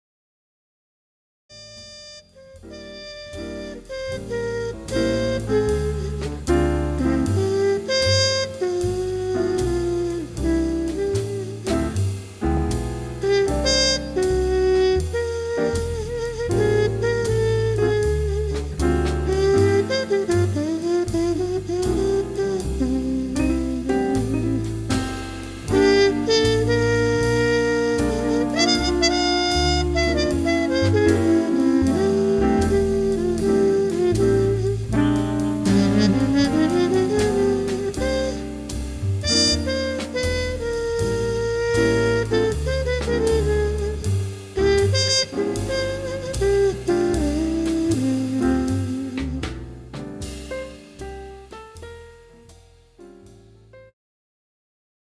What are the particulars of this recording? Recorded at Clowns Pocket Studio, London 2013